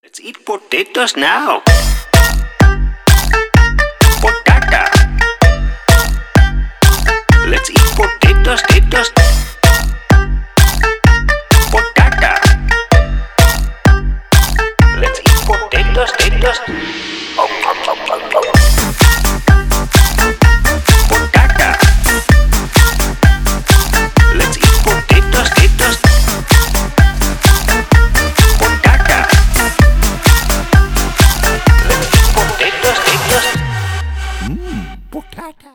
Веселые мелодии